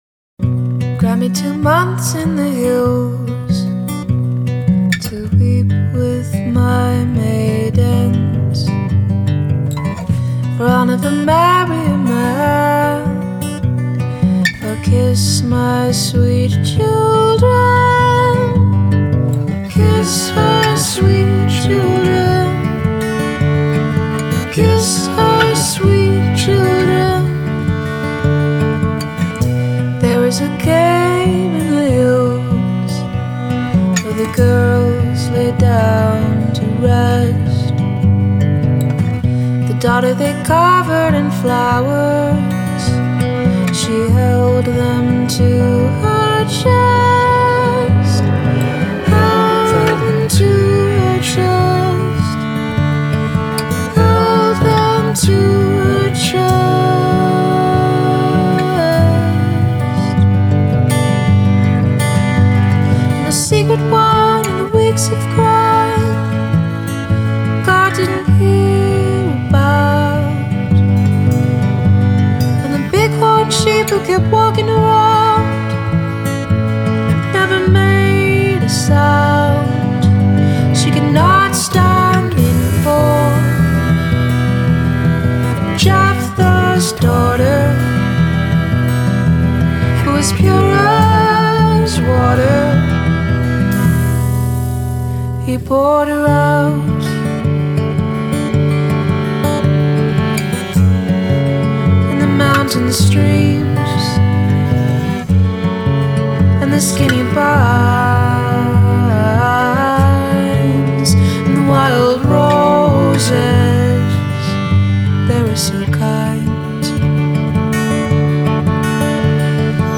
folksy album